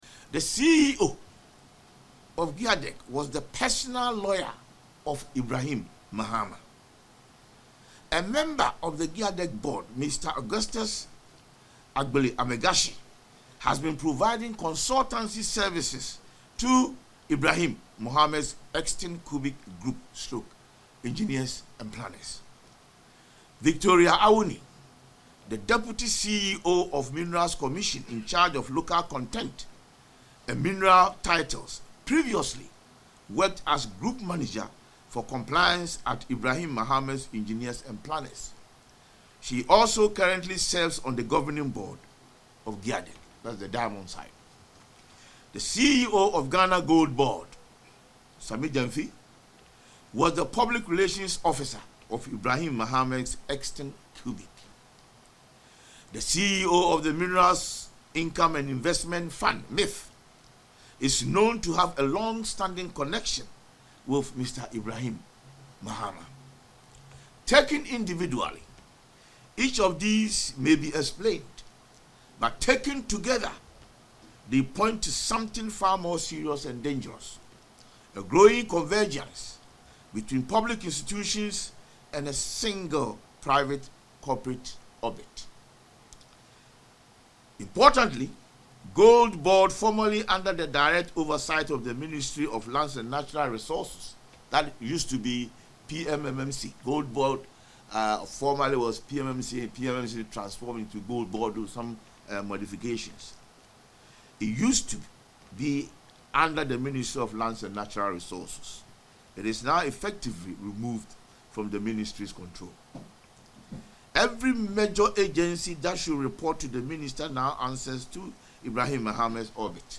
Addressing journalists in Parliament, the minority spokesperson on lands and natural resources, Kwaku Ampratwum-Sarpong, alleged that since the president’s return to office, companies linked to Ibrahim Mahama have gained unprecedented and unfair advantages.